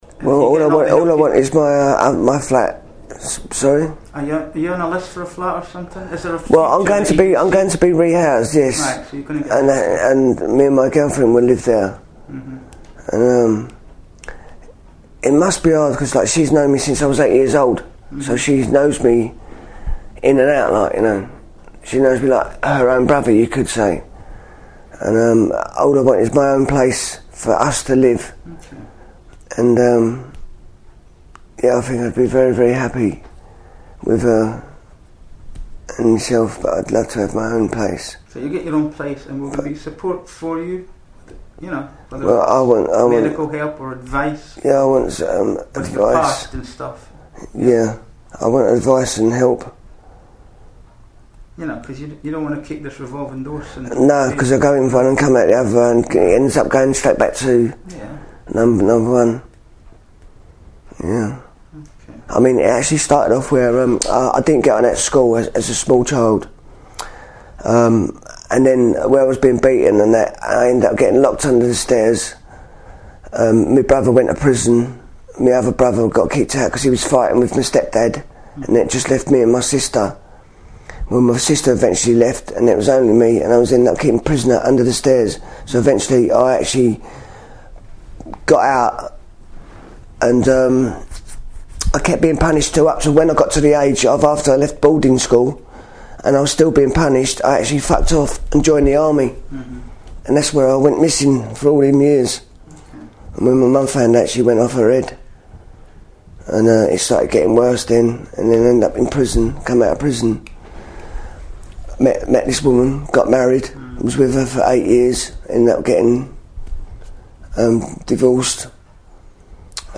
Some interviews contain strong language.